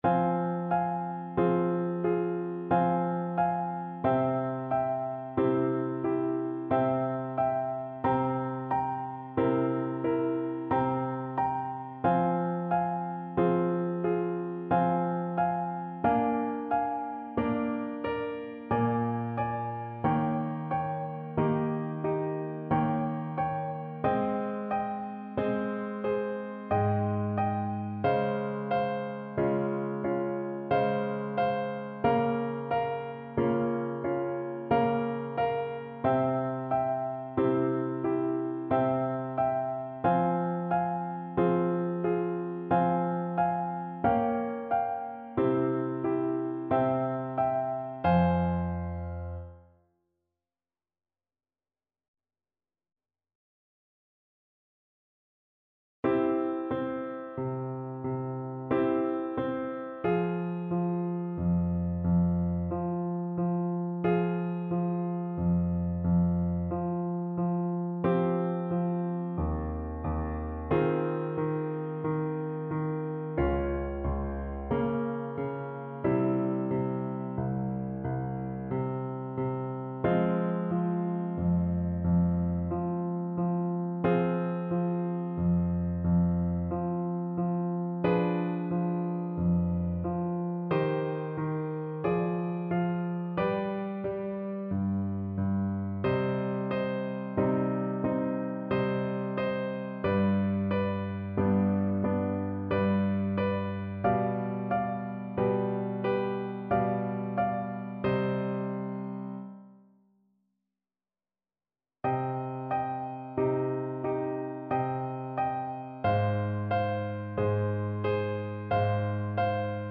Free Sheet music for Voice
Play (or use space bar on your keyboard) Pause Music Playalong - Piano Accompaniment Playalong Band Accompaniment not yet available transpose reset tempo print settings full screen
3/4 (View more 3/4 Music)
Bb4-Db6
F minor (Sounding Pitch) (View more F minor Music for Voice )
Largo =c.60
Classical (View more Classical Voice Music)